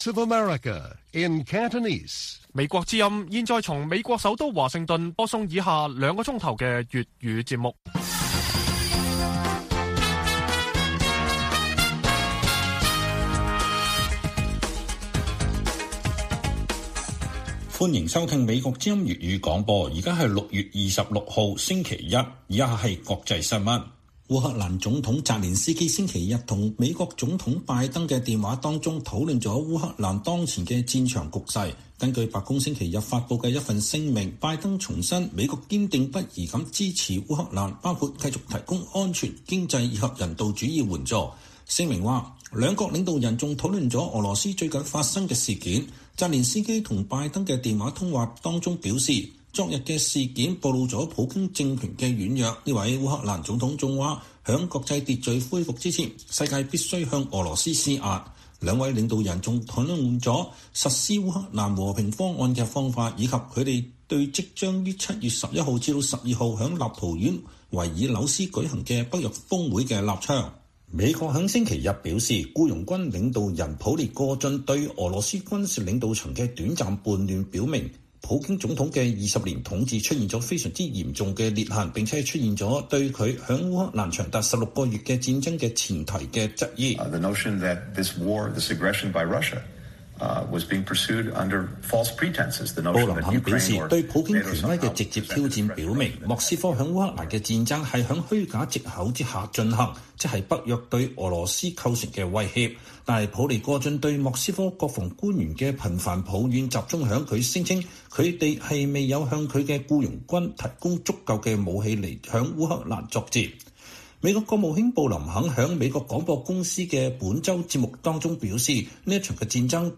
粵語新聞 晚上9-10點: 拜登和澤連斯基討論俄烏戰局